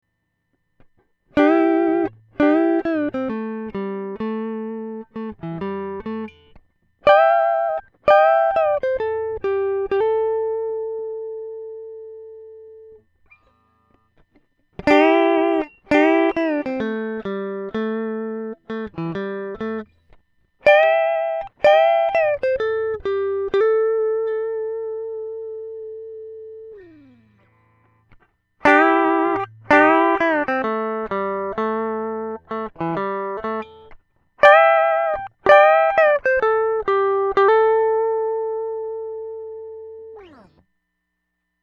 LEAD2の痛い部分を適度に和らげてくれつつも、全く異なった音にはならず、尚且つエアー感のようなものまで付加されるかのようです。
こちらが43を搭載した音源です。フロント→ミックス→リアの順です。
録音もPC直で同様です。